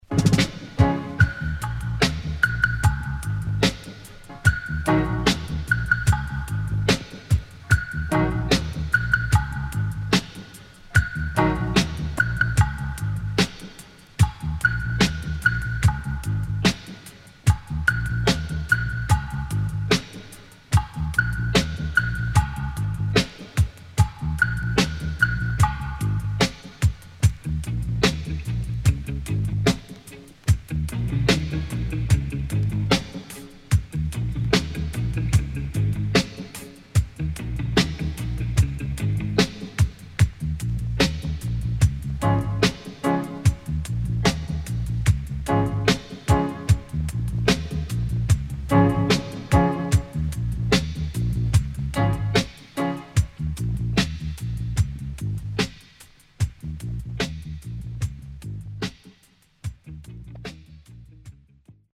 HOME > REISSUE [DANCEHALL]
Killer One Drop